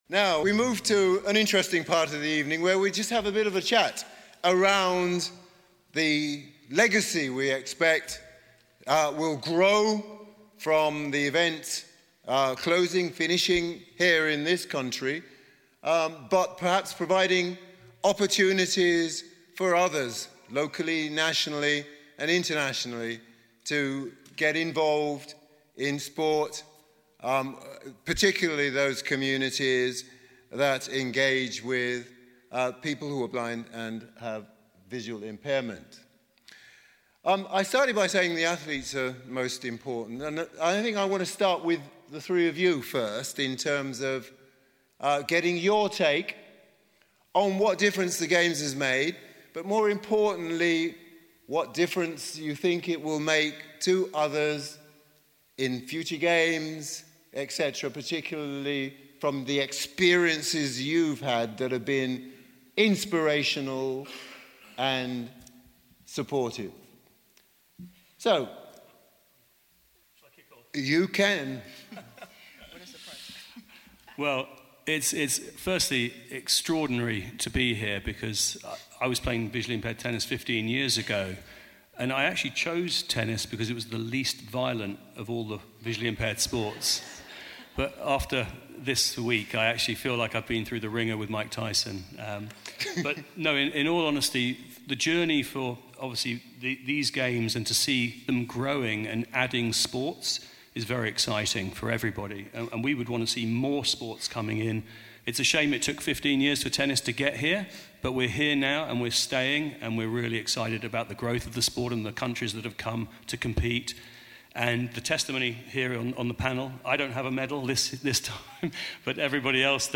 IBSA World Games 2023 - Closing Ceremony - Round Table Discussion